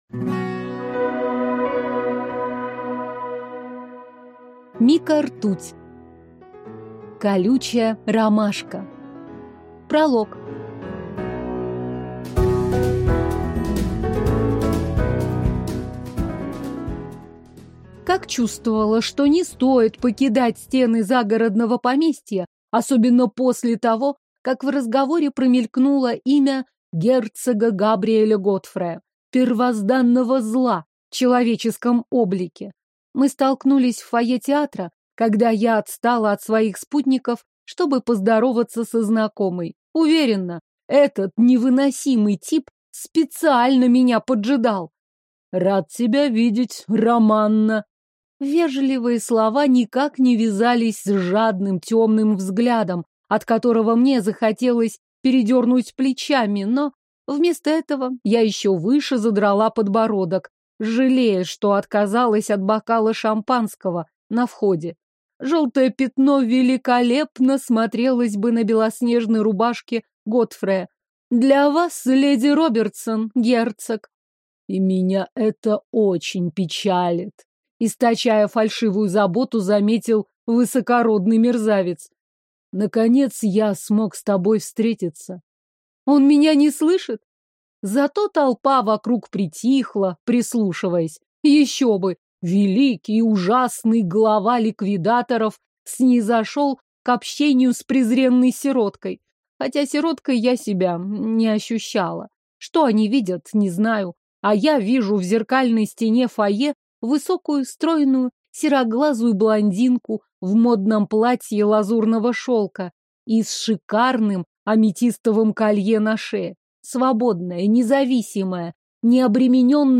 Аудиокнига Колючая ромашка | Библиотека аудиокниг